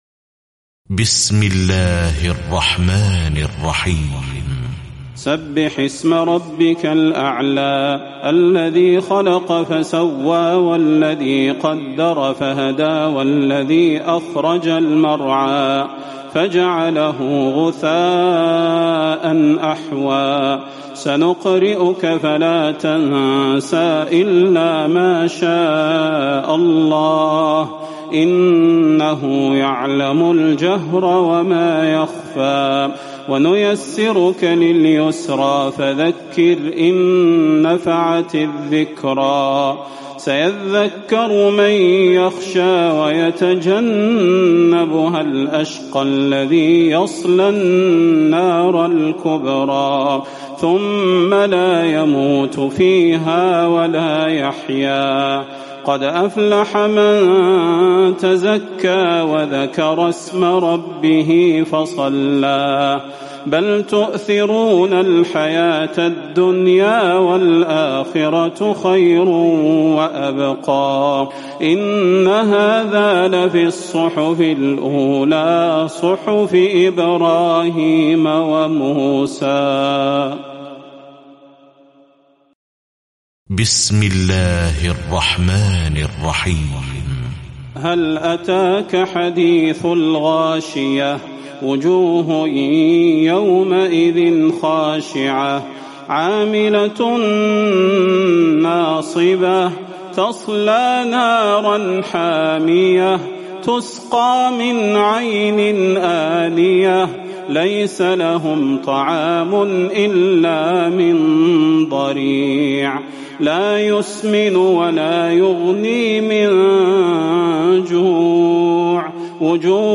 تراويح ليلة 29 رمضان 1438هـ من سورة الأعلى الى الناس Taraweeh 29 st night Ramadan 1438H from Surah Al-A'laa to An-Naas > تراويح الحرم النبوي عام 1438 🕌 > التراويح - تلاوات الحرمين